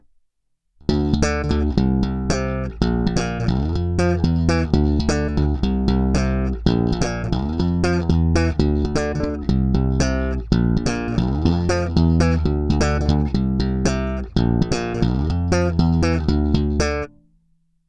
Nejstarší kousek má i nejdřevitější zvuk.
Následující nahrávky byly provedeny rovnou do zvukovky. Použité struny byly nějaké niklové padesátky ve slušném stavu.
Bonusové nahrávky - basy a výšky přidány přibližně o polovinu rozsahu